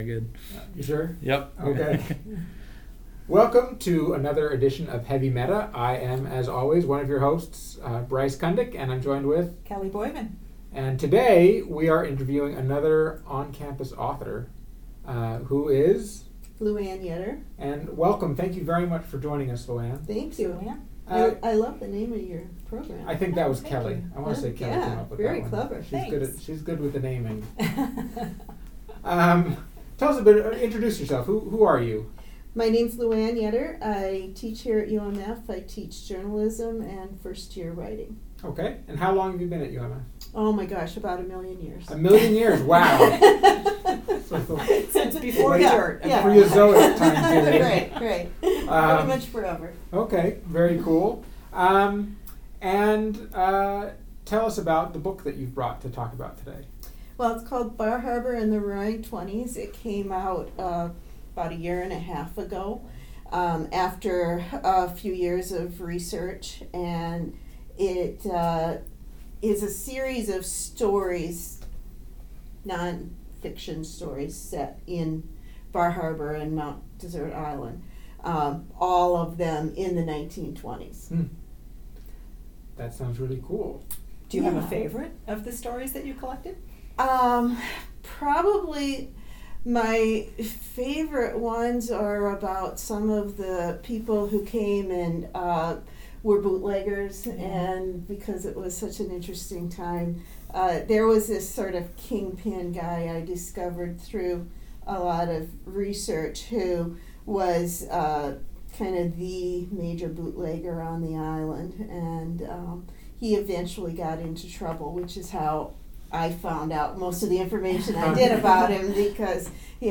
Another in our series of interviews with UMF authors.